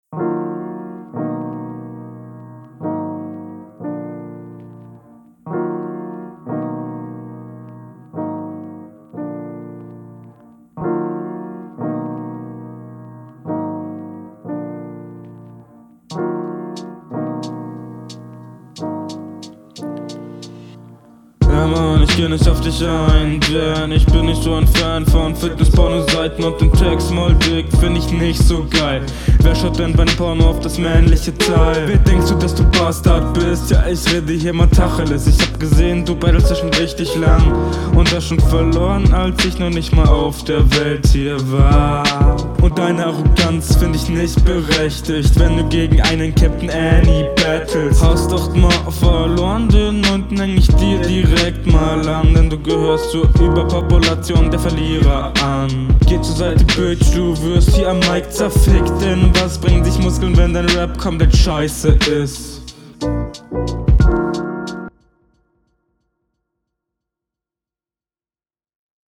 schwierig, die aufnahme etwas besser als davor, finde den beat unpassen dfür ein battle, hast …